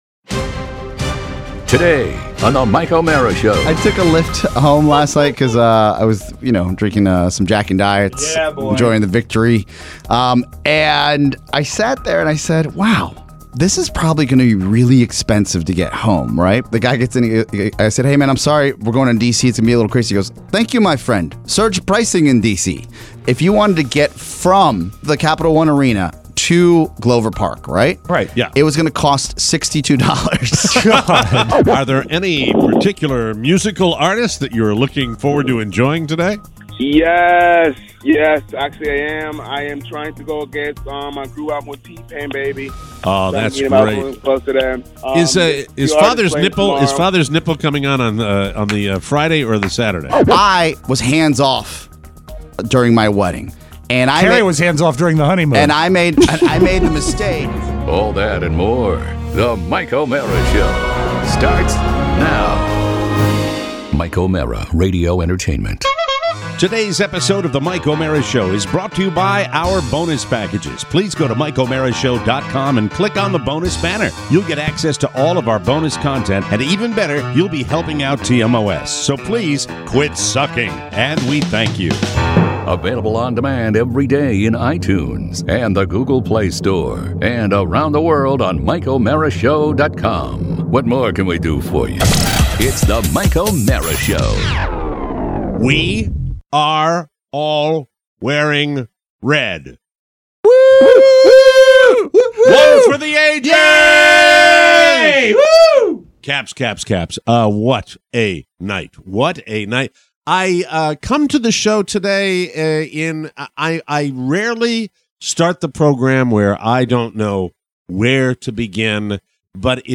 live from Bonnaroo